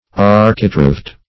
Architraved \Ar"chi*traved\, a.